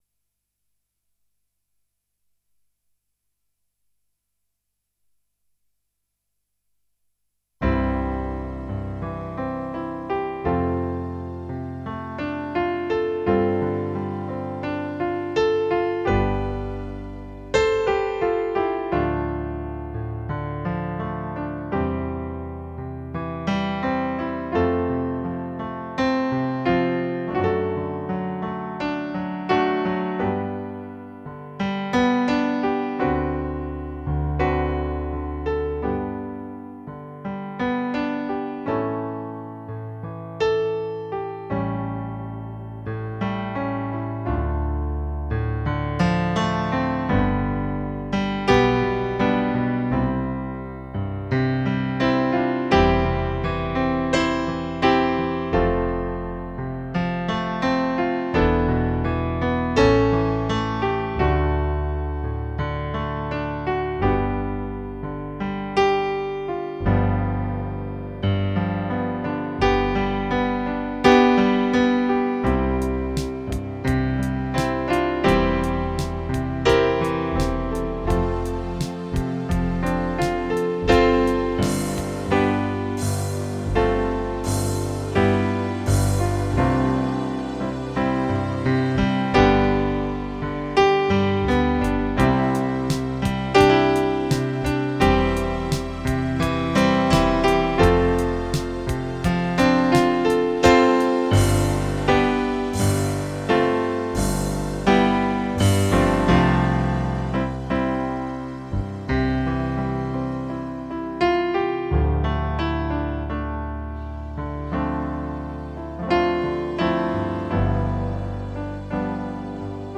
Instrumental (Audio)